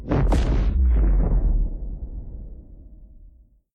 sounds_explosion_scifi.ogg